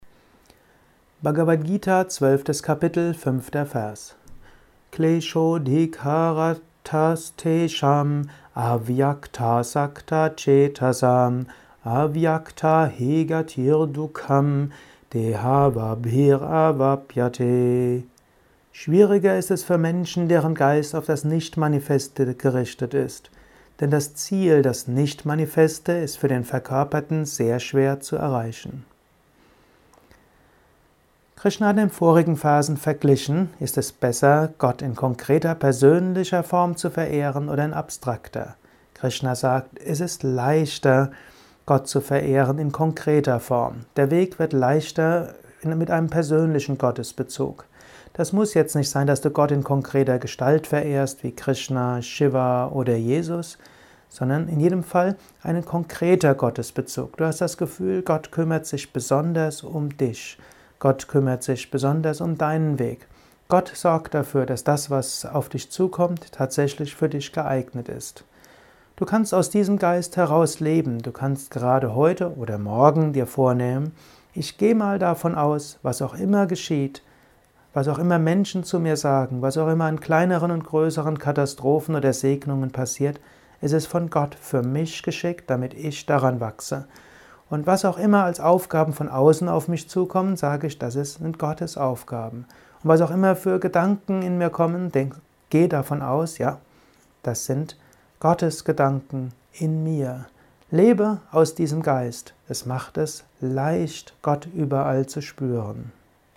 Dies ist ein kurzer Kommentar als Inspiration für den heutigen
Aufnahme speziell für diesen Podcast.